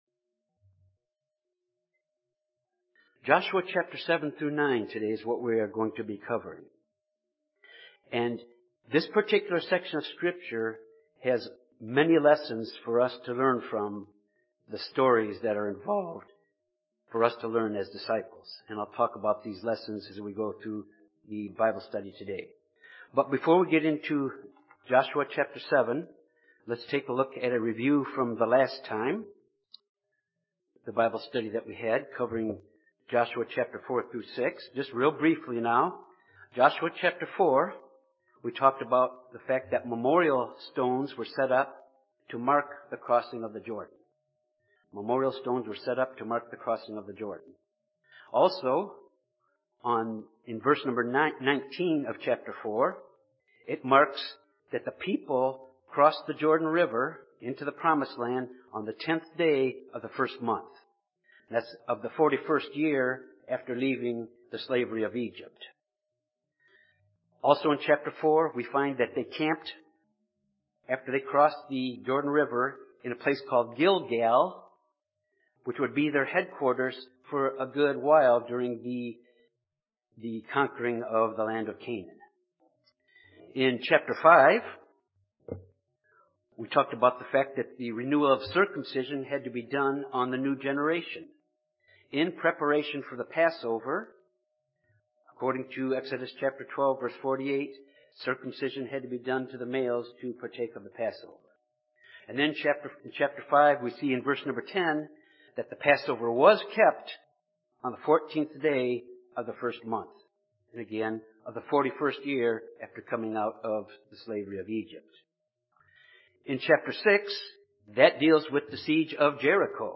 This Bible study covers Joshua, chapters 7-9. These chapters covers the defeat of Israel at Ai because of Achan's sin of secretly taking a golden idol as plunder contrary to God's instructions.